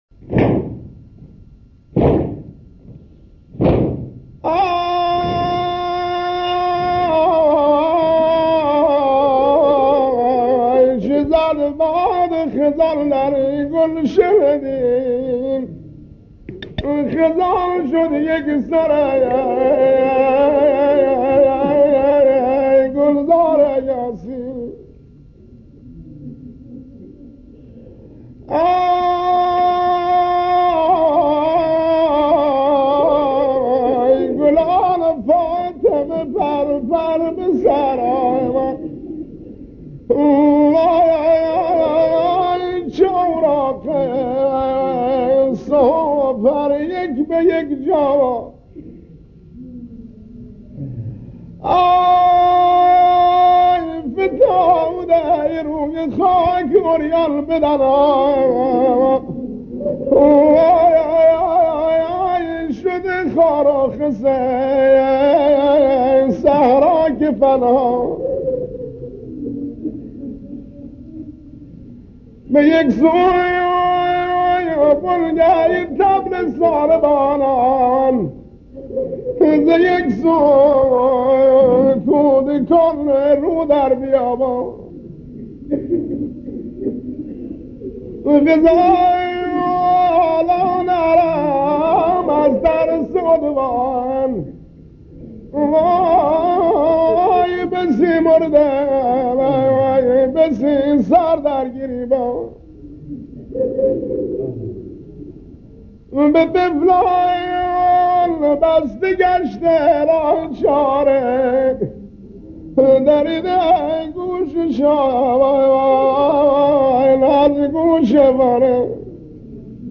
وبلاگ تخصصی نوحه های سنتی بوشهر
مرثیه